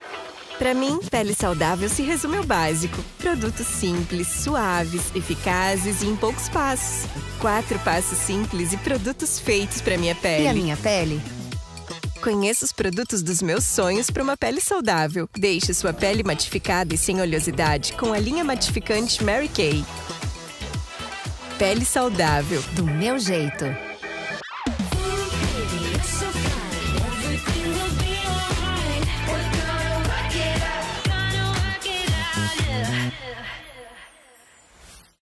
locutora brasileira, com voz jovem adulta, apta para locuções em diversos estilos, versatilidade e qualidade de entrega.
Sprechprobe: Werbung (Muttersprache):
I am always looking to deliver the best quality, without leaving the quality and punctuality of delivery, I can speak with a neutral accent and also a Gaucho accent from the south of Brazil.